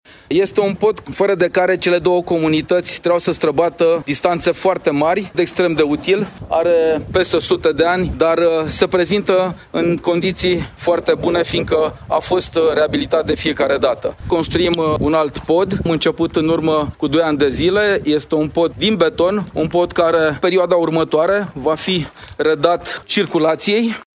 Președintele Consiliului Judetean Brașov, Adrian Veștea: